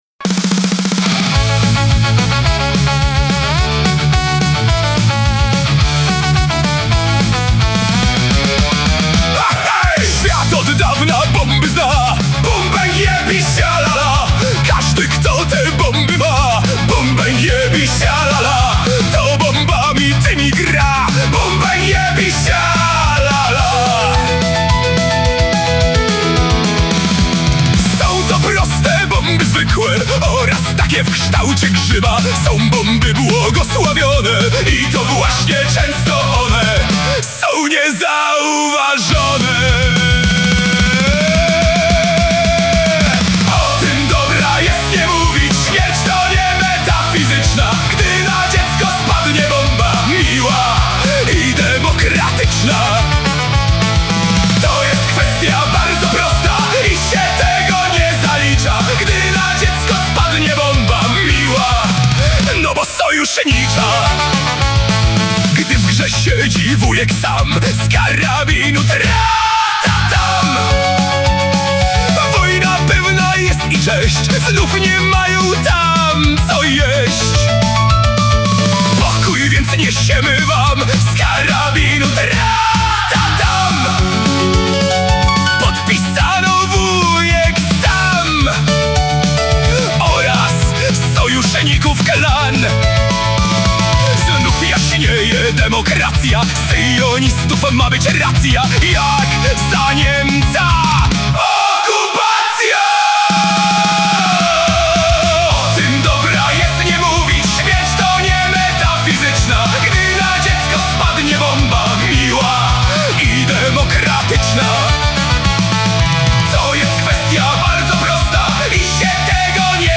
produkcja AI.